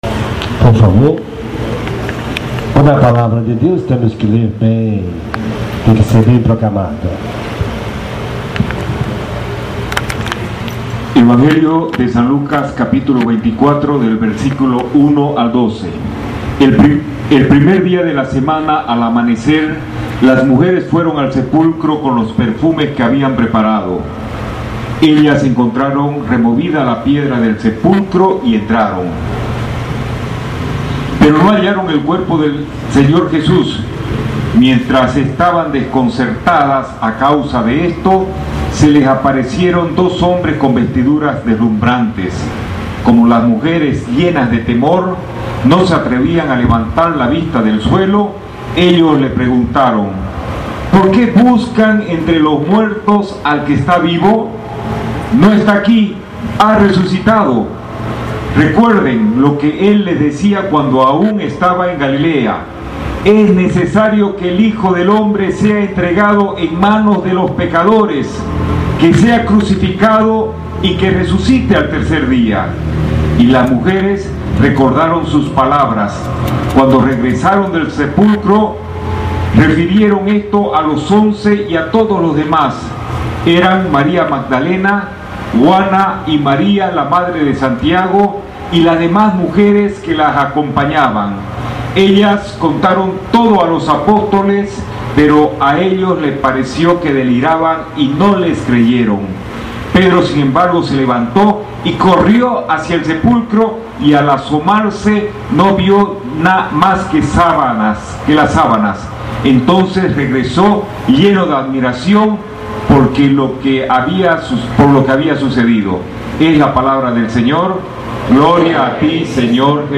Homilía en Vigilia Pascual